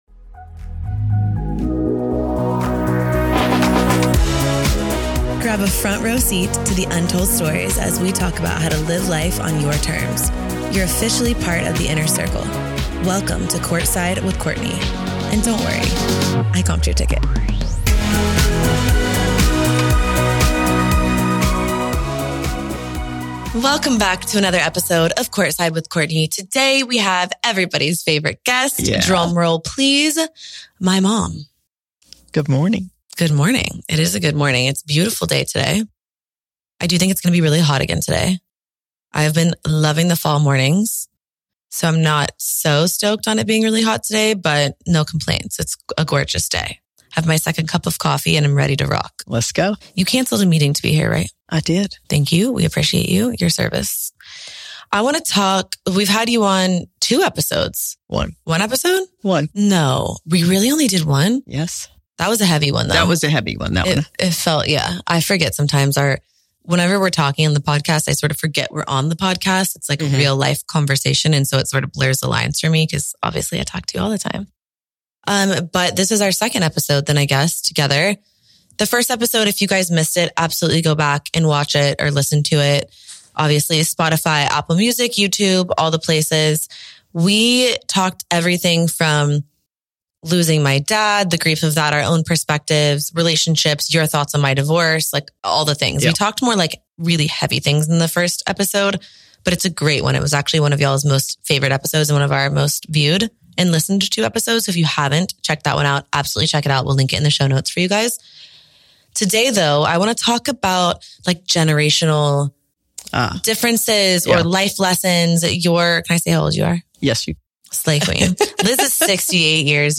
Society & Culture, Entrepreneurship, Relationships, Business, Personal Journals